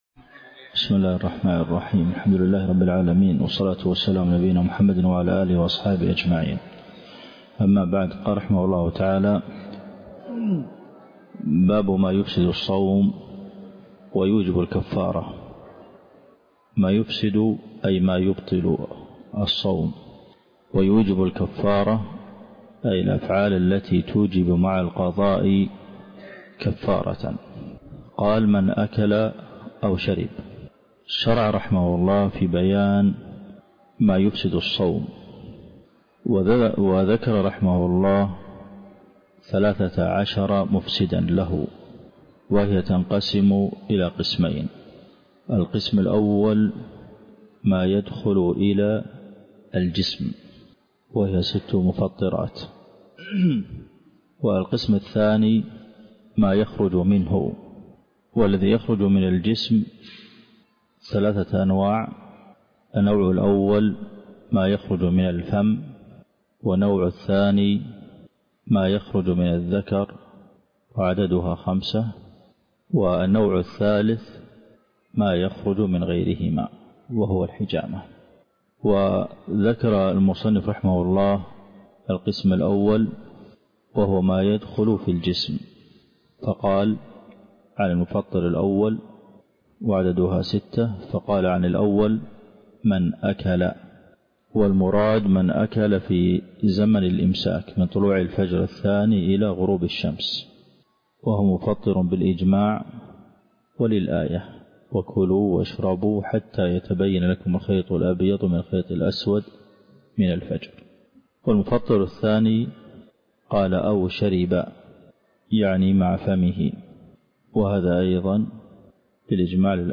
الدرس (6) شرح زاد المستقنع دورة في فقه الصيام - الشيخ عبد المحسن القاسم